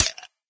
icemetal3.ogg